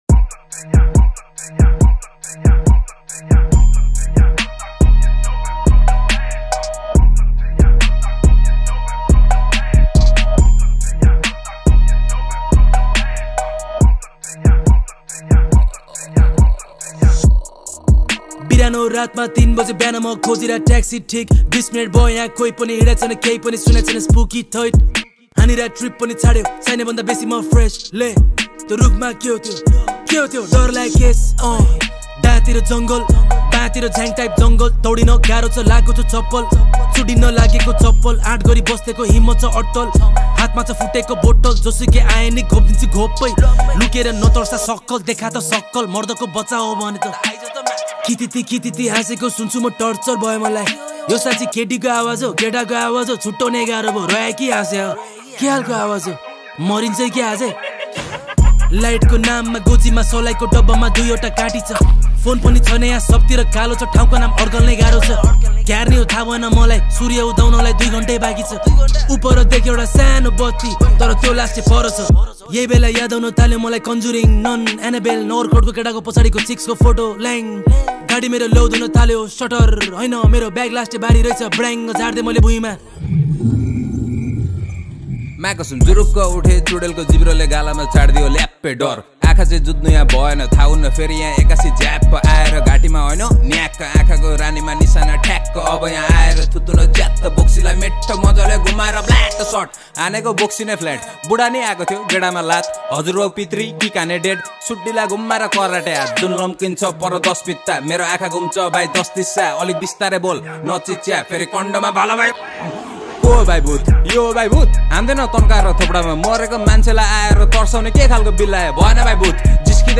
# Nepali Hiphop Song